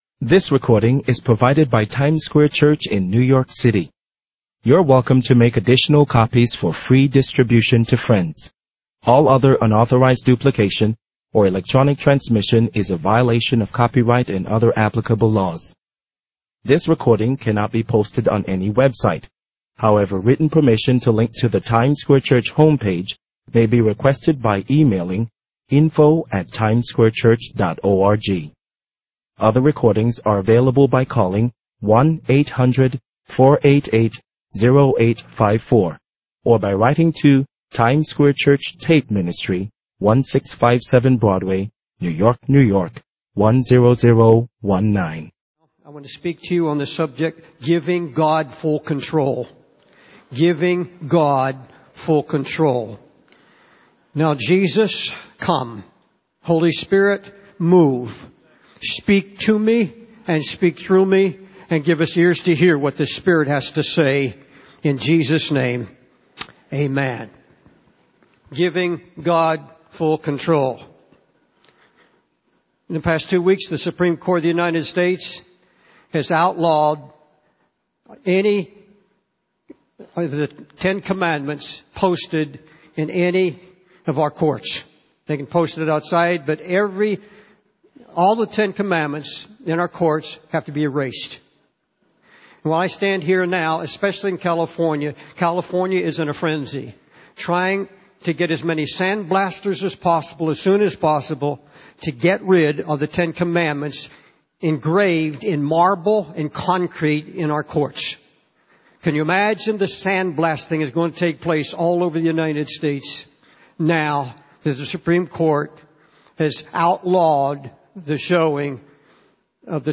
In this sermon, the speaker emphasizes the importance of waiting on God and giving Him full control in our lives.